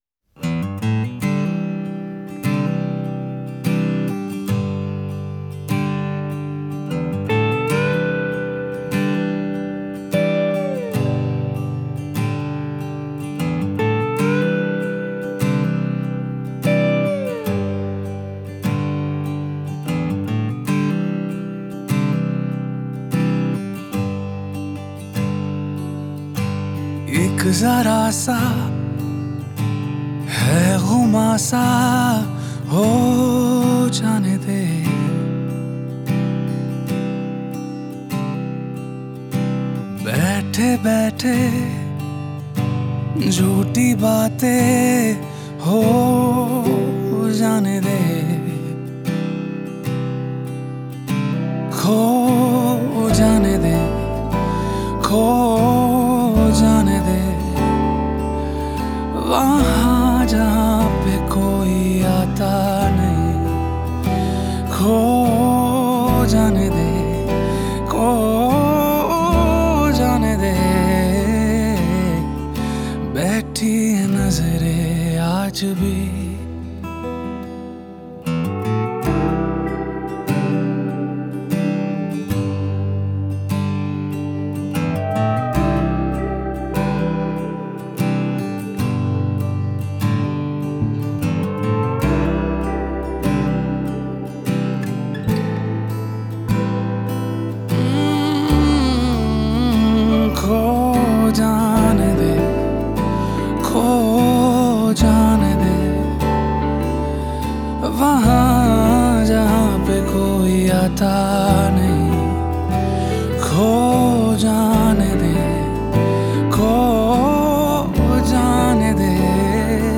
hindi song